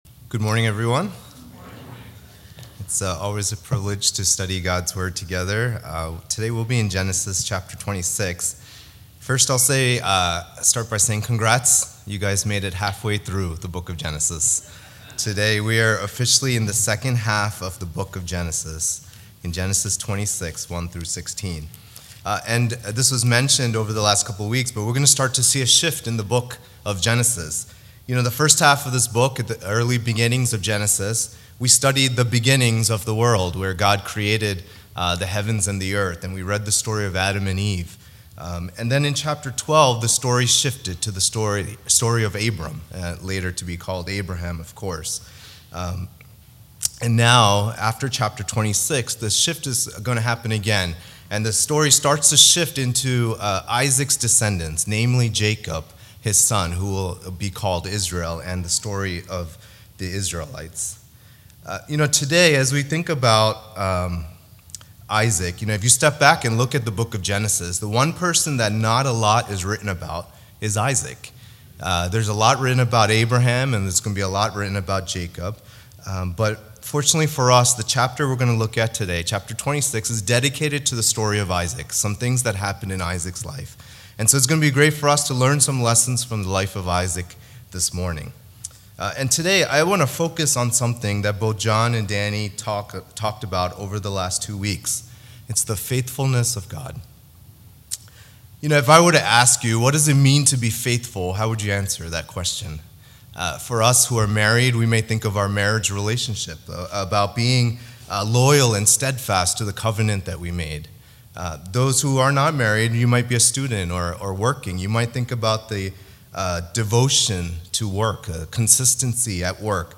All Sermons